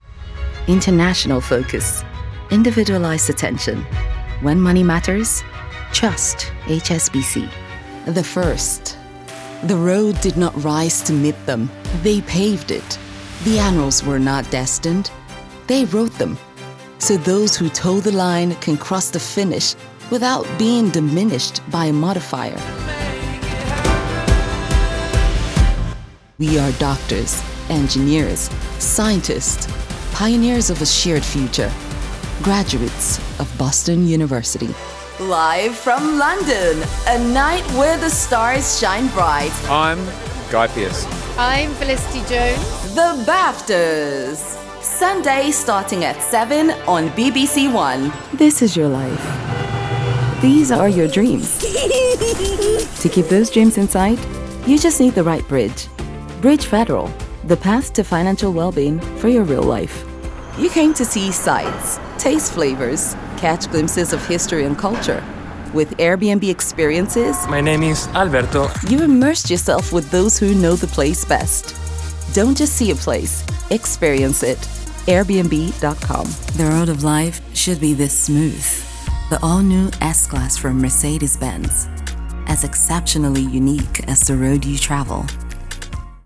Commercial Demo ENGLISH